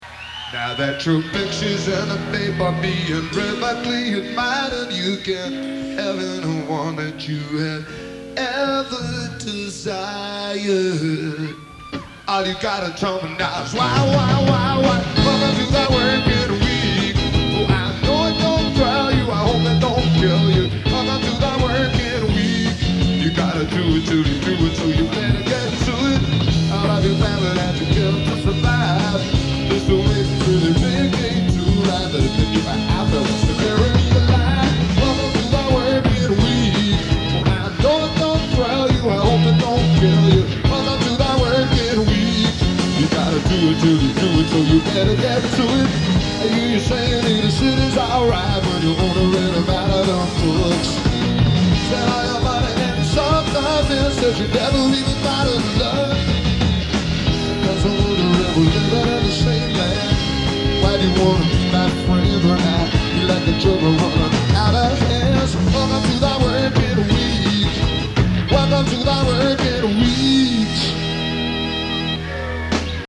Live at The Riviera, Chicago, Illinois, 3 Dicembre 1977